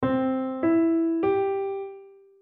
Rozpoznawanie trybu melodii (smutna,wesoła)